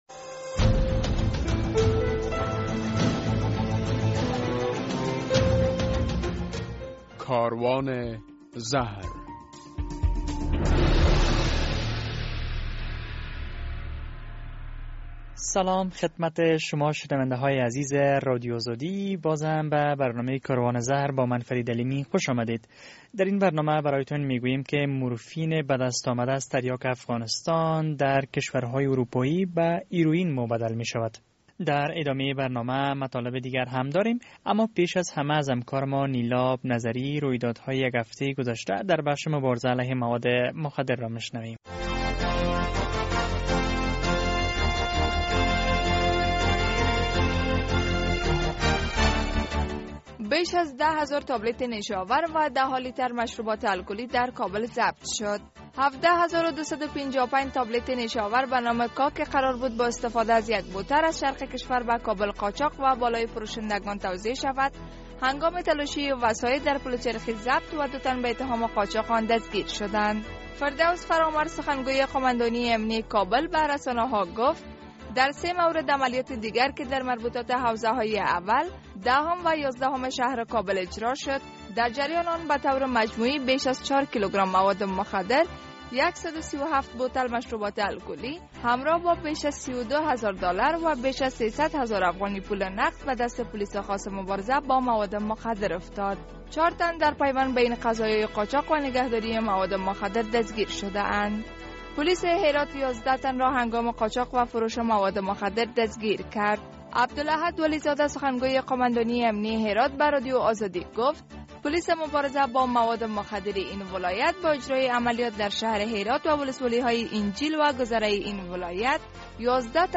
در برنامه این هفته کاروان زهر این مطالب گنجانیده شده اند: رویدادهای مهم در بخش مواد مخدر، در یک گزارش می‌شنوید که مورفین بدست آمده از تریاک افغانستان در اروپا به هیرویین تبدیل می‌شود، در یک گزارش دیگر می‌شنوید که باشندگان سرای شمالی کابل از فروش آزاد مواد مخدر شکایت دارند، در مصاحبه با یک داکتر...